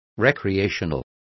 Complete with pronunciation of the translation of recreational.